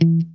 FUNKMUTE4-1.wav